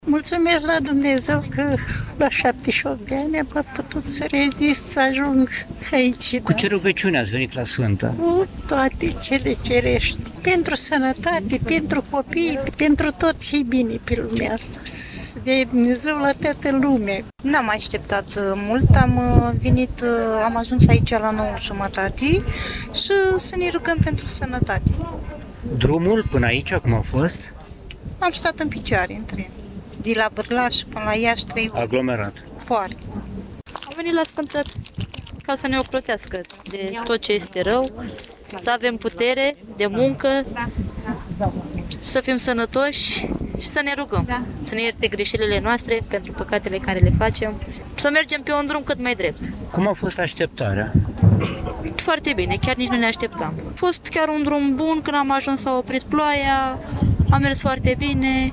Credincioșii ne-au împărtășit motivele pentru care au venit să se roage, dar și cum a fost așteptarea:
14-oct-rdj-8-vox-pelerini.mp3